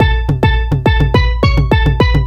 Занимательная перкуссия.